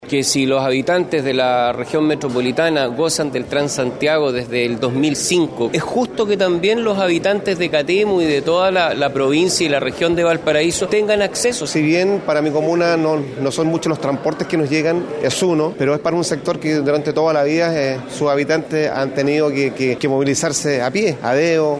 Por otro lado, los alcaldes de Catemu y Panquehue, Rodrigo Díaz y Gonzalo Vergara, respectivamente, valoraron la iniciativa que beneficiará a ambas comunas.
cu-transporte-zonas-rurales-alcaldes.mp3